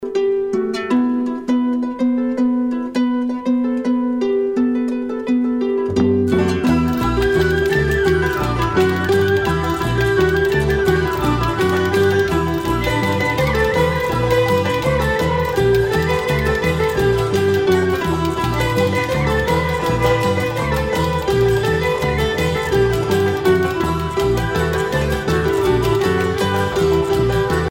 danse : bal (Bretagne)
Groupe celtique
Pièce musicale éditée